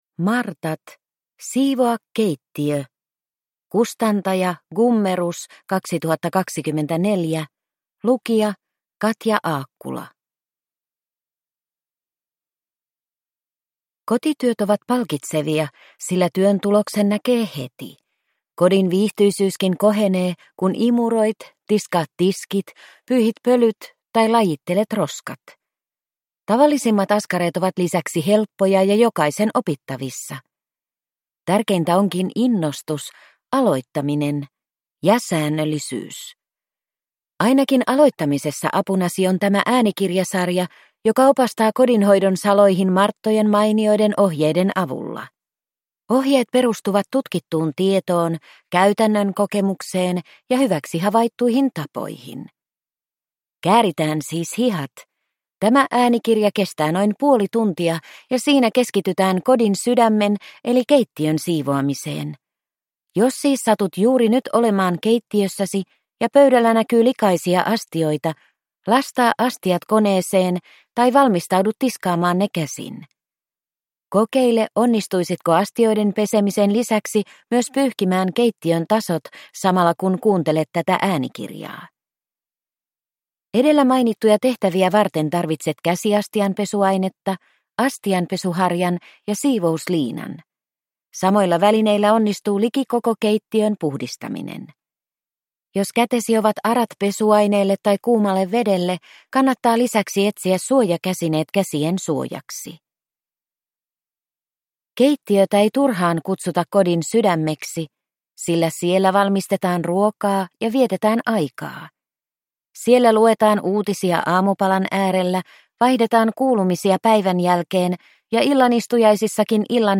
Martat - Siivoa keittiö – Ljudbok